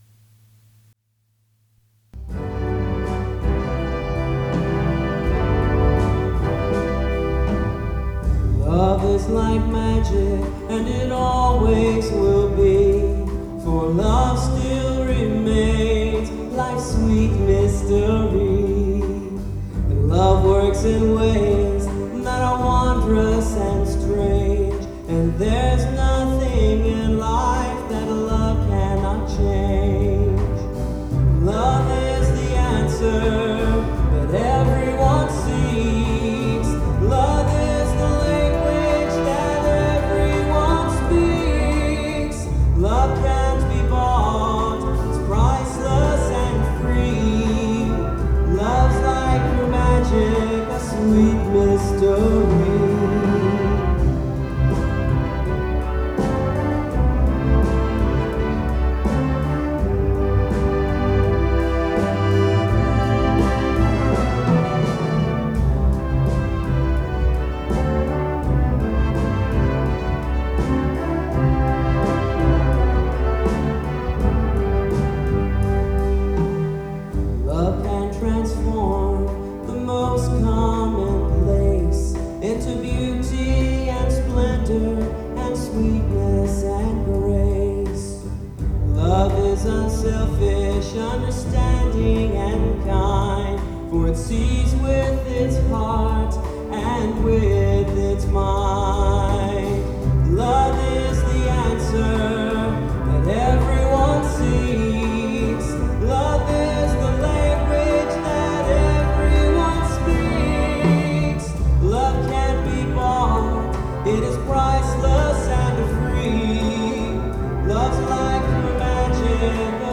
瑞穂ユースオーケストラの演奏と太平洋空軍バンドの歌手によってスカイホールで披露された。
その時に録音されたものである。